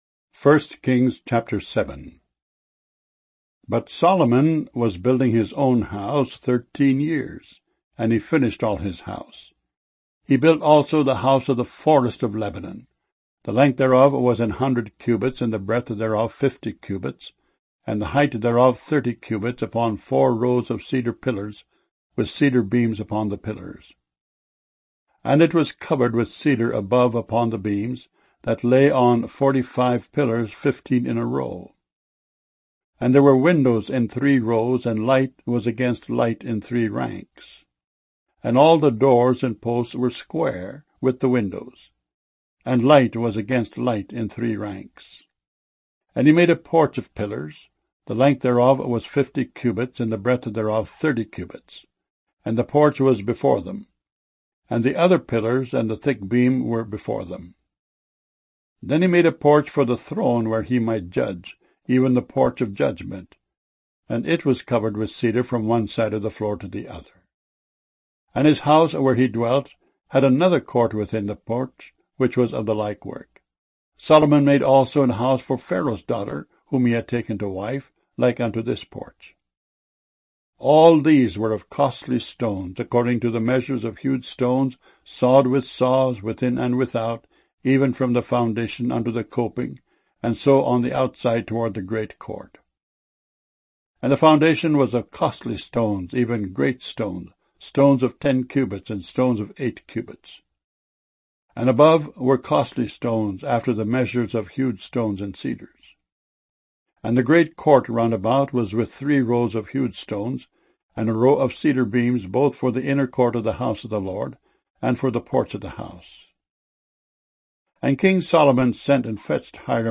Streaming MP3 Audio Bible files mono 32 kbs small direct from wav files